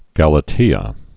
(gălə-tēə)